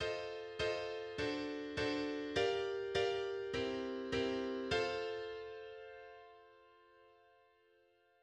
Jazz-blues_turnaround.mid.mp3